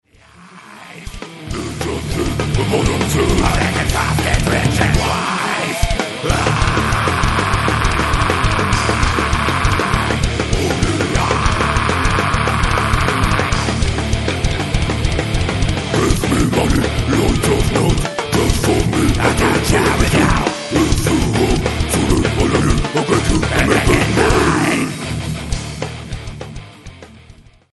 das ist brachialer Metal "voll auf die Fresse"!
Vocals
Guitars
Bass
Drums